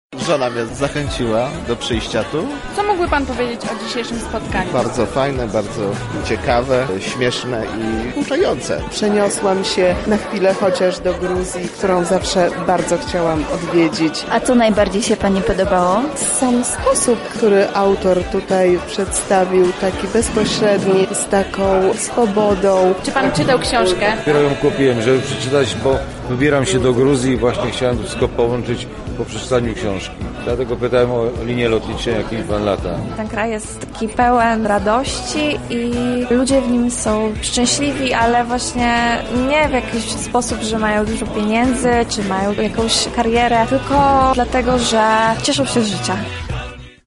Wczoraj dziennikarz pojawił się w Centrum Spotkania Kultur, aby porozmawiać o nowym wydaniu książki „Gaumardżos”.